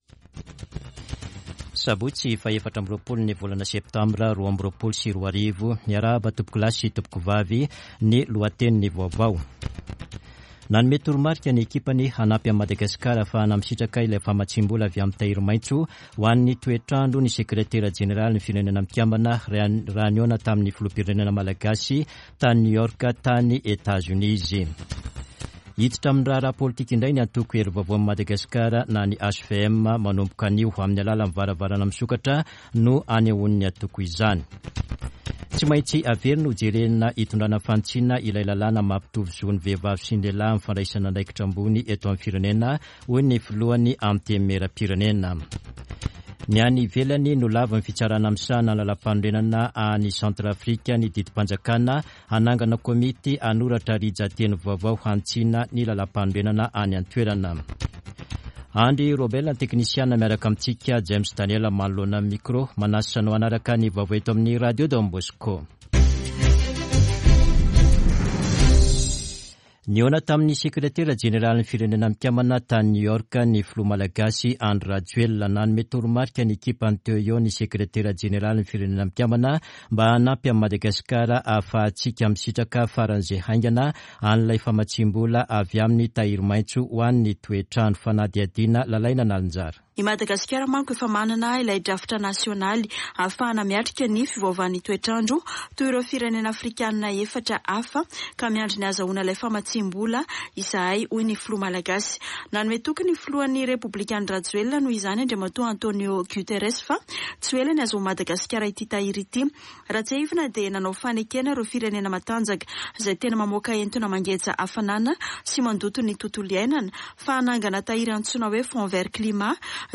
[Vaovao maraina] Sabotsy 24 septambra 2022